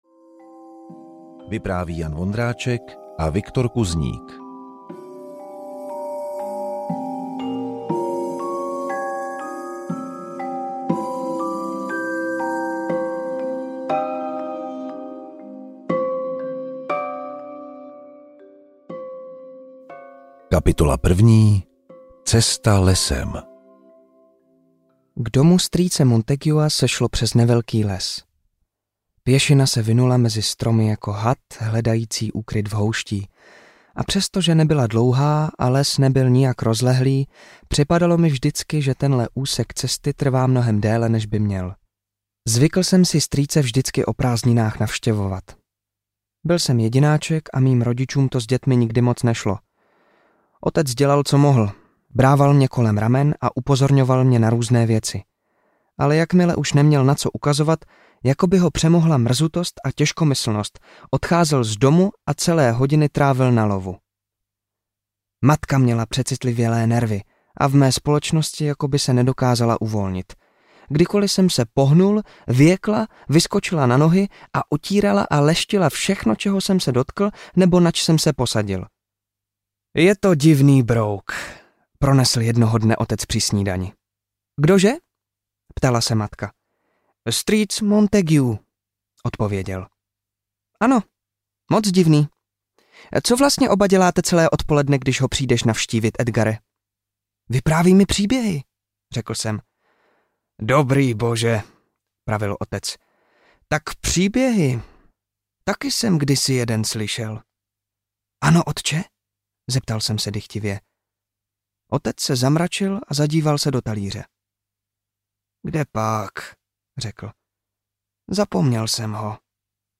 Příšerné příběhy strýce Montaguea audiokniha
Ukázka z knihy